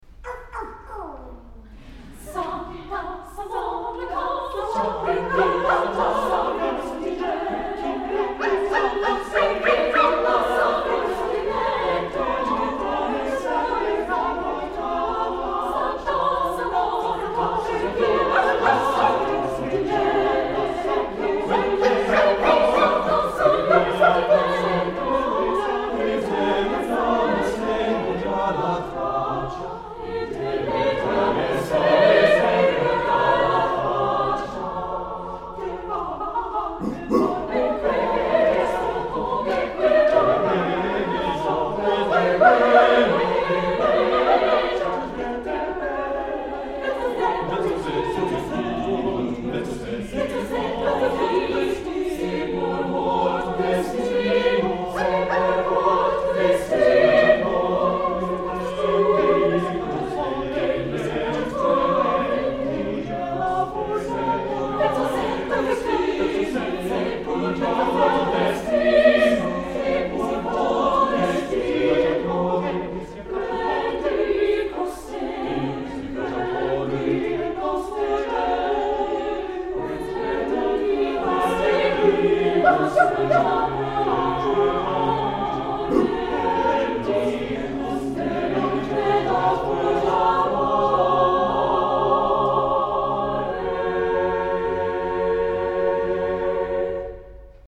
Voicing: SSATB
Instrumentation: a cappella
This is a very lively madrigal about romantic love.